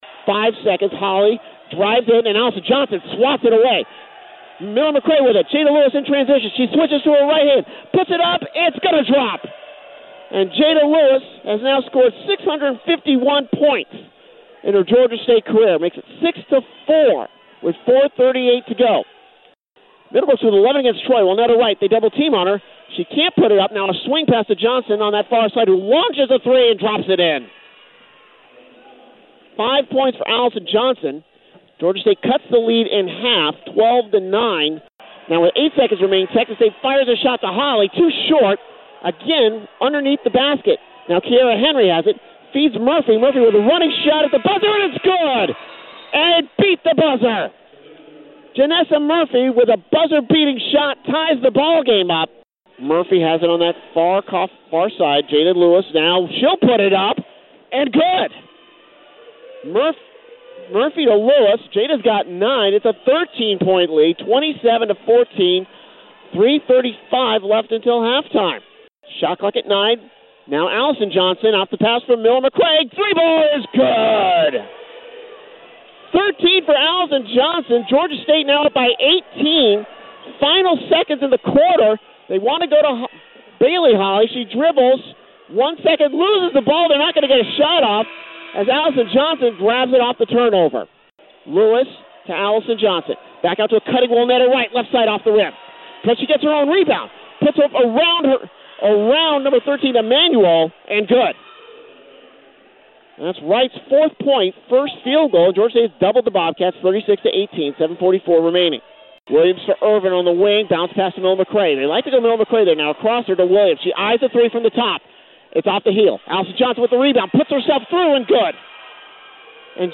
A collection of my play-by-play clips.